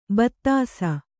♪ battāsa